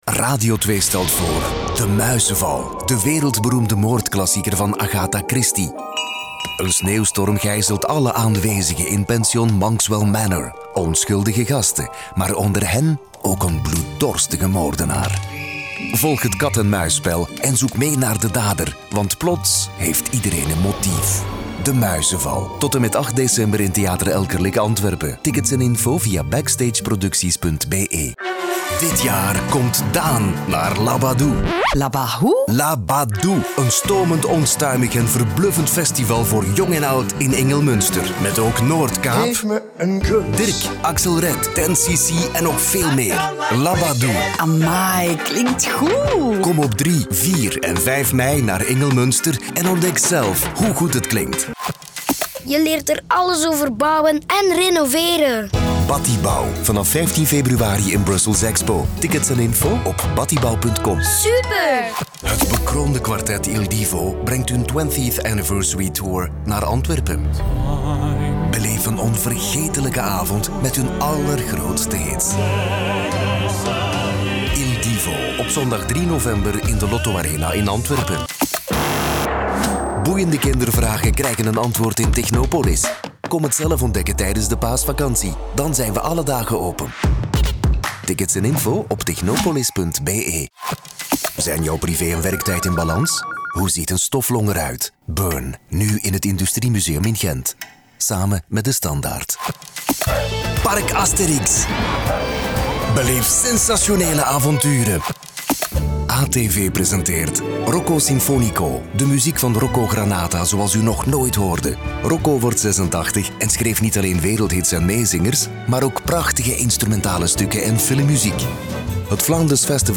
Cálida, Profundo, Seguro, Maduro, Accesible
Comercial